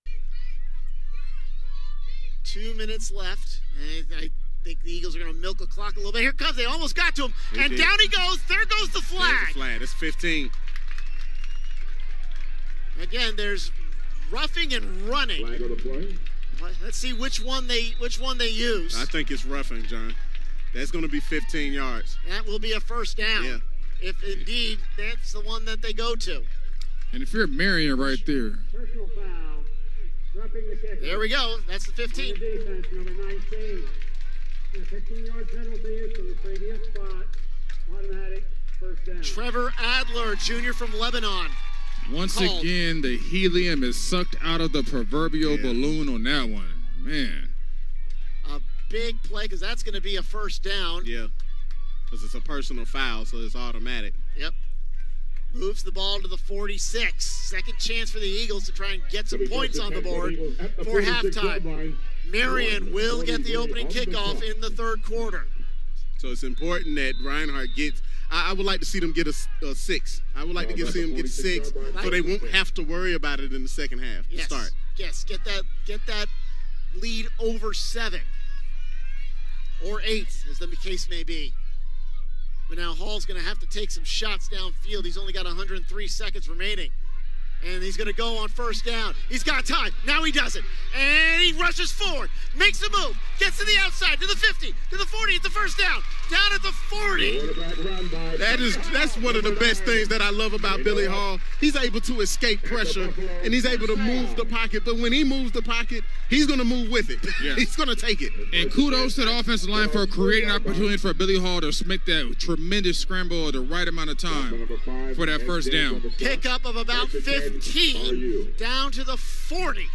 A collection of my play-by-play clips.